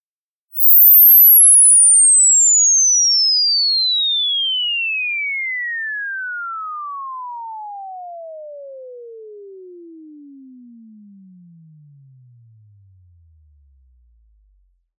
Invsweep.wav